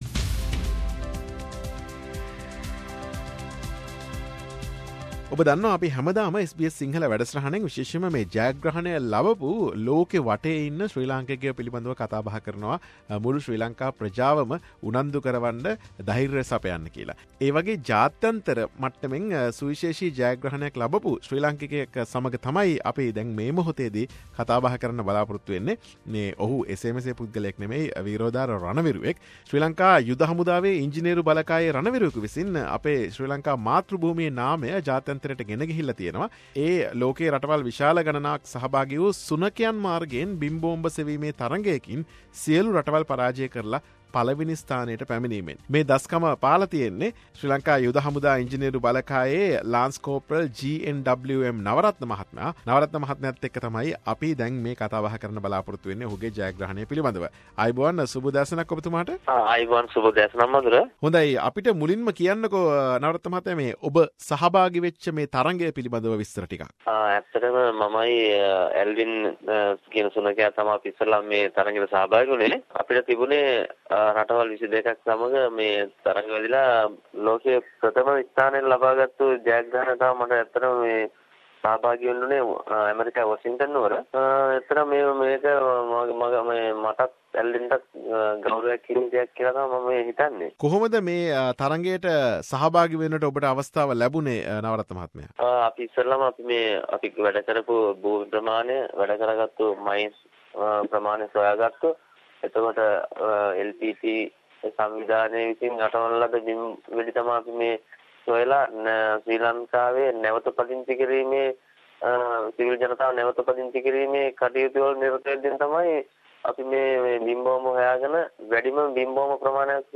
The discussion